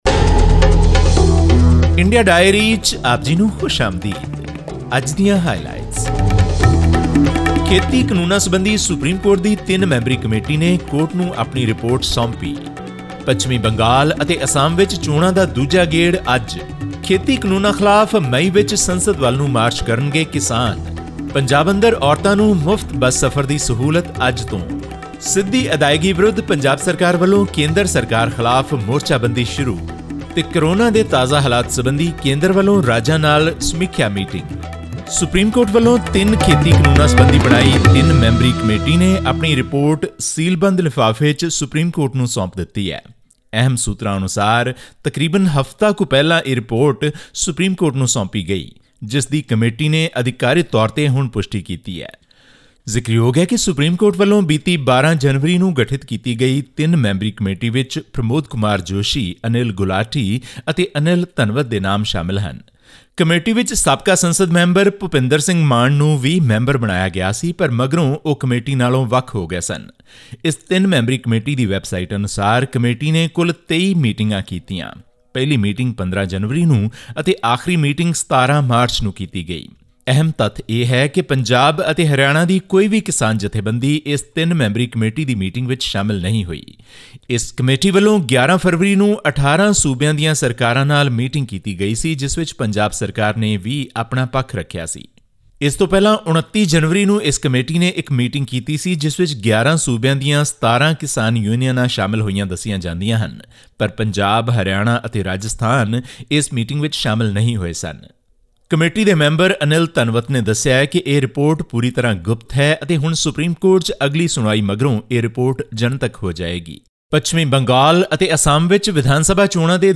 All this and more in our weekly news segment from India.